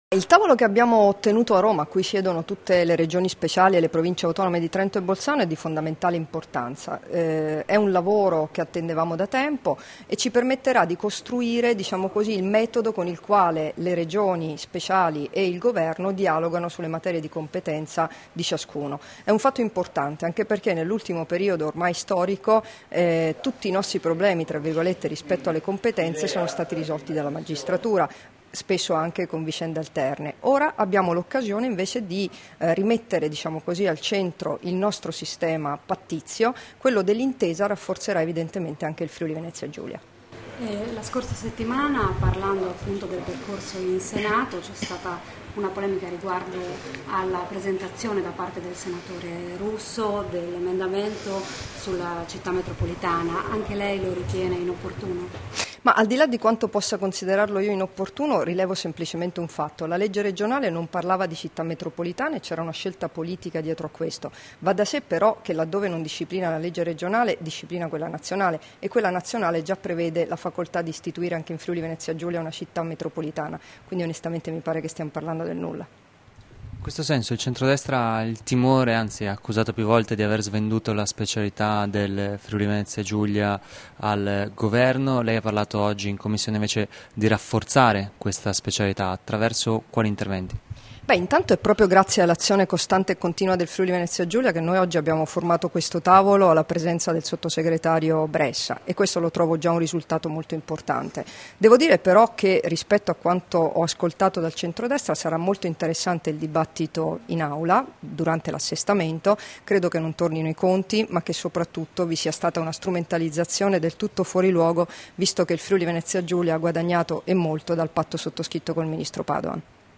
Dichiarazioni di Debora Serracchiani (Formato MP3) [1925KB]
rilasciate a margine dell'audizione in V Commissione in Consiglio regionale, a Trieste il 14 luglio 2015